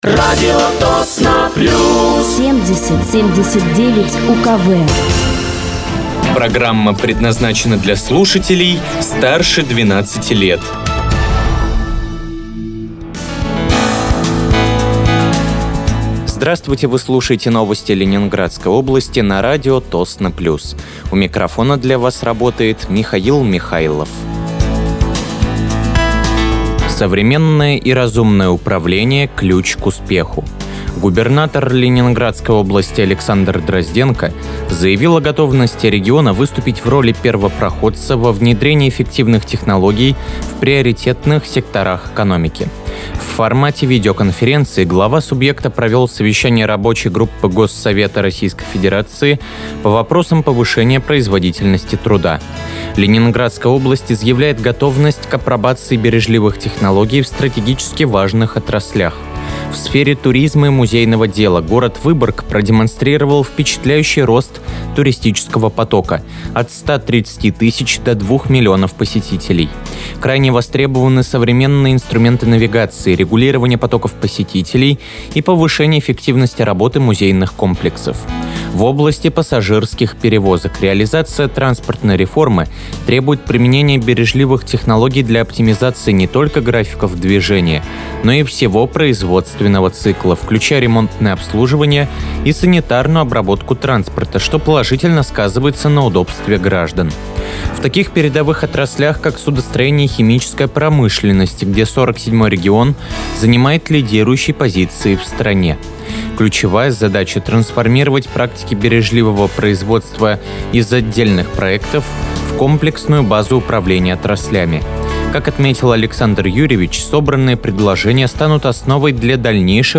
Выпуск новостей Ленинградской области от 13.10.2025
Вы слушаете новости Ленинградской области от 13.10.2025 на радиоканале «Радио Тосно плюс».